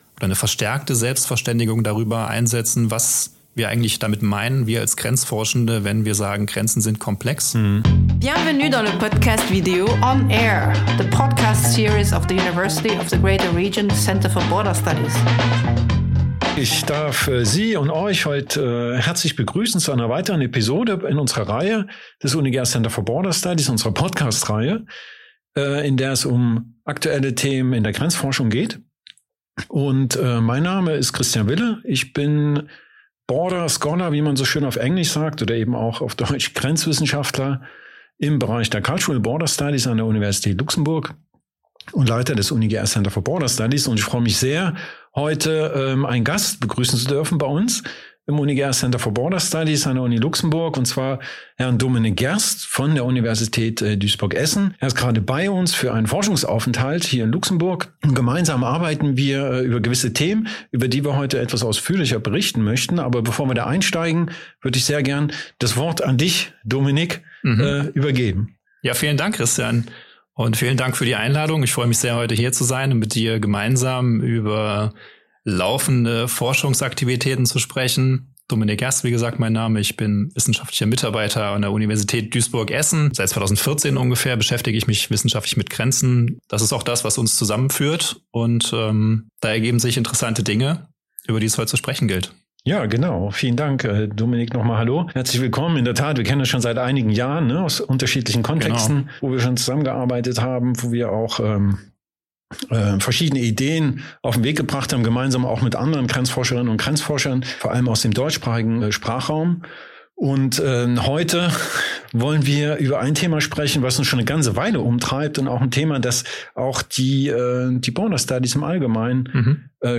Im Gespräch wird deutlich, dass Grenzen nicht feststehen, sondern immer wieder neu hergestellt und erlebt werden. Die kulturwissenschaftlichen Grenzforscher diskutieren, wie man solche Prozesse untersuchen kann, ohne sie aber zu vereinfachen.